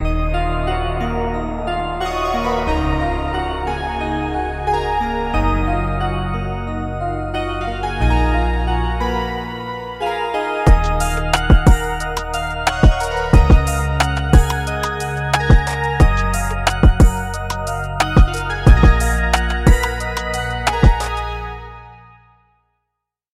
Karanyi Sounds Vapor Keys 2 是一款专为 **KONTAKT** 设计的音色库，它包含了精心录制的 FM 和模拟经典合成器的声音。根据第一个搜索结果¹，它可以让你回到 90 年代的复古氛围，提供了 90 个工厂预设，涵盖了键盘、铃声、合成器和垫音等类型。它适合制作现代的音乐风格，如嘻哈、陷阱、故障嘻哈、低保真、蒸汽波或浩室音乐。